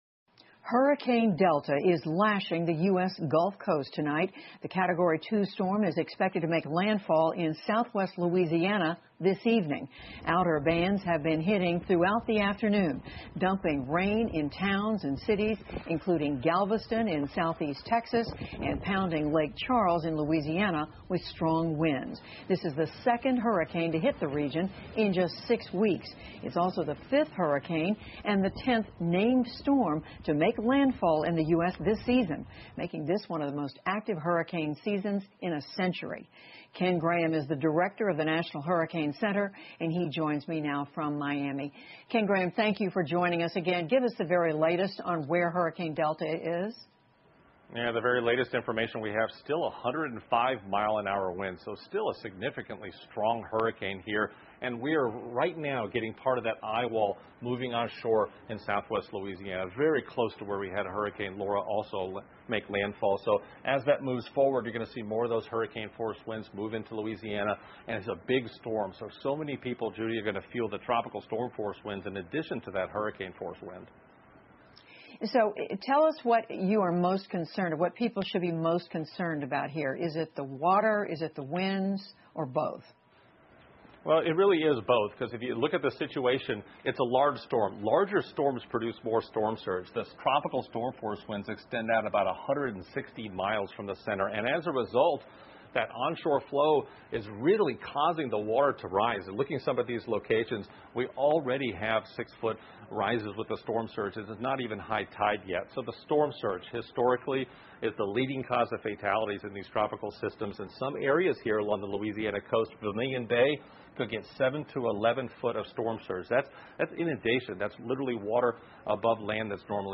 PBS高端访谈:飓风德尔塔袭击海湾沿岸 听力文件下载—在线英语听力室